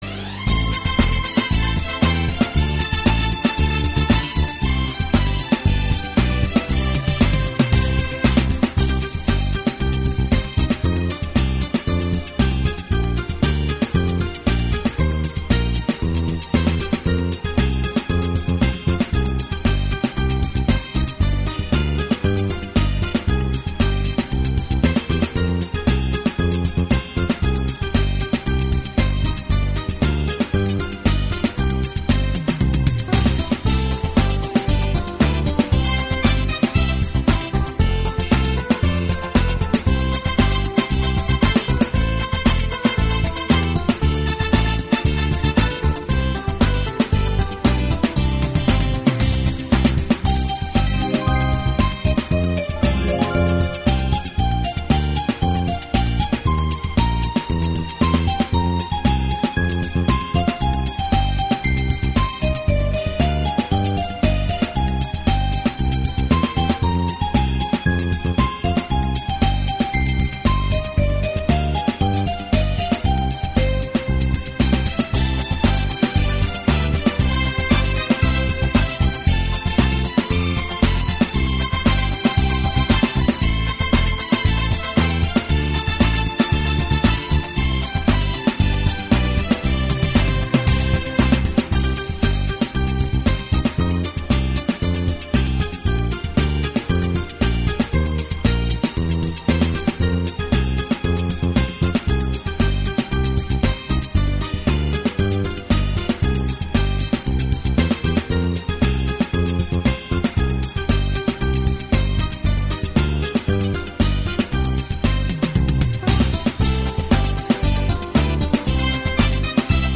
Предлагаем вам музыкальное сопровождение для выполнения упражнений.
Утренняя_гимнастика_младшая_группа.mp3